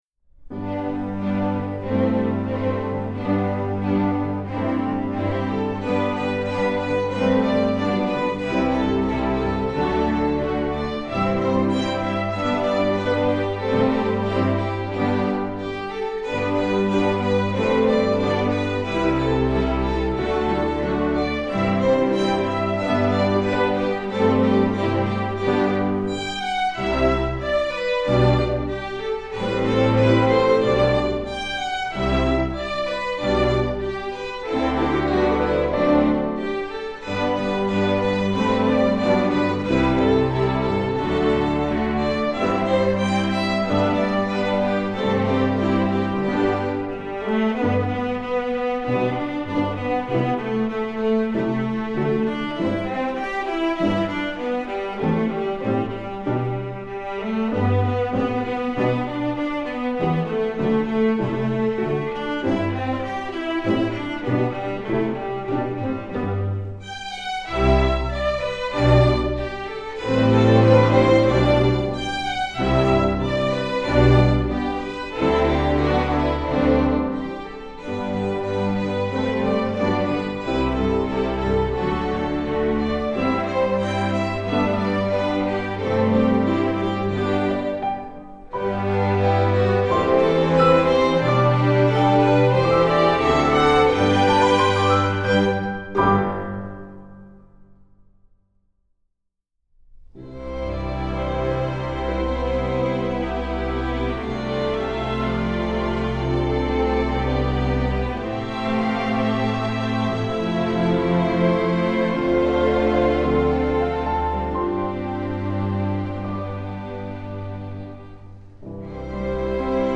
classical, irish, concert, instructional, children